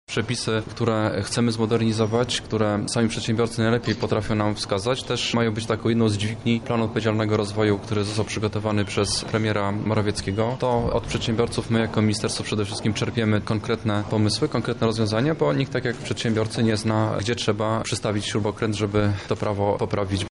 – mówi Mariusz Haładyj.